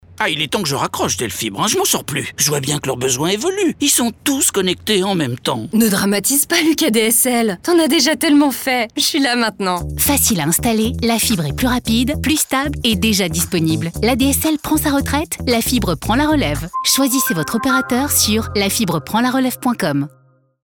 • Spot radio La relève 20 secondes